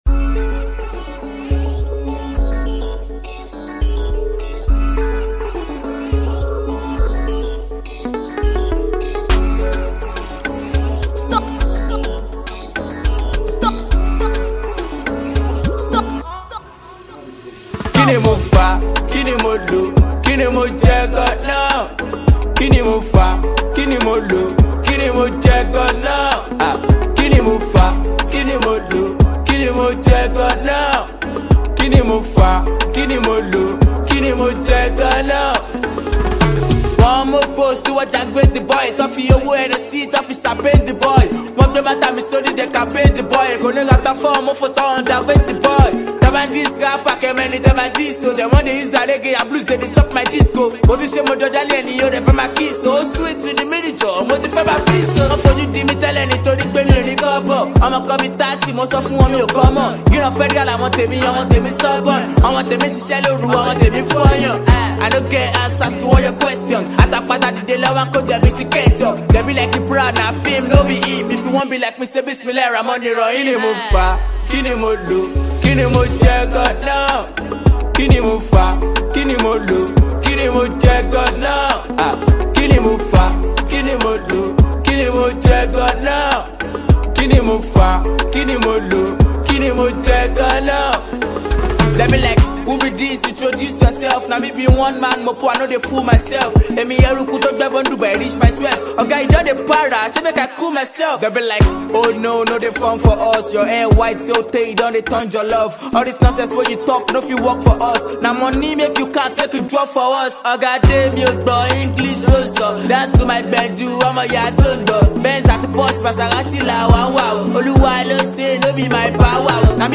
street anthem